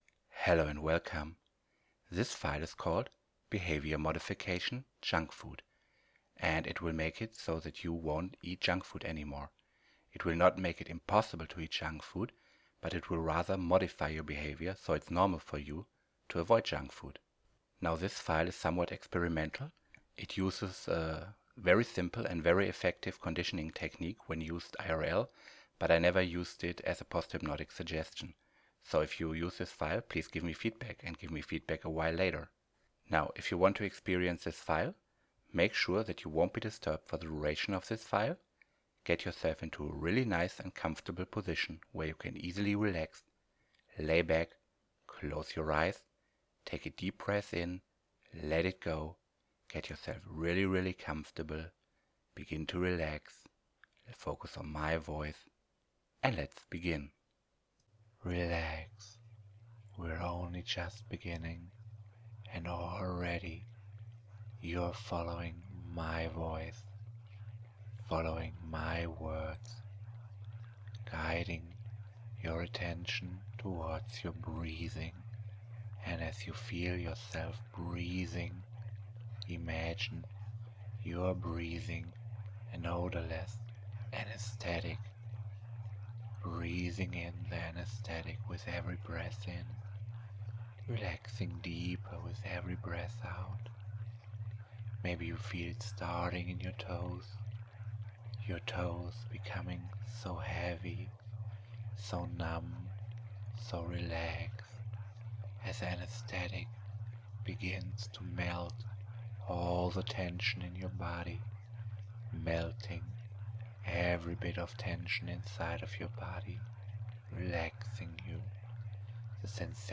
You will become uninterested in junk food with this behavior modification recording.